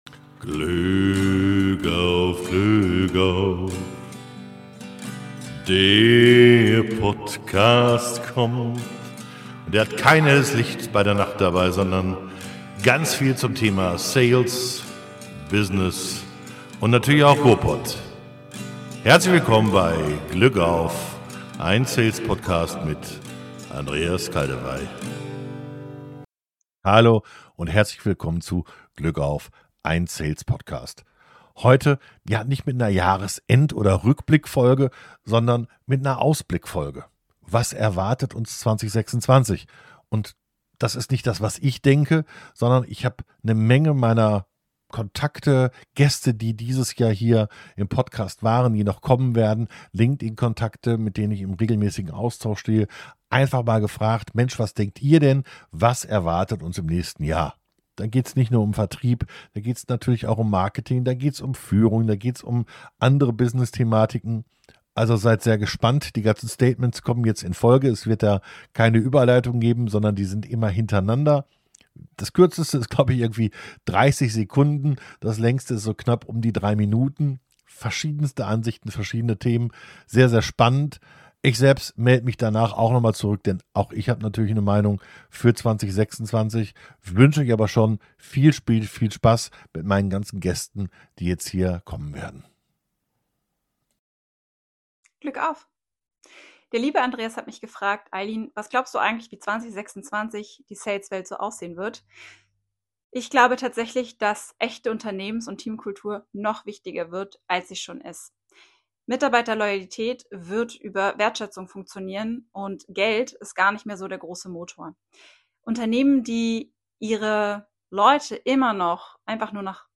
37. Quo vadis 26? Ein Jahresforecast mit ganz vielen Gästen ~ GLÜCK AUF! - Ein Sales PottCast Podcast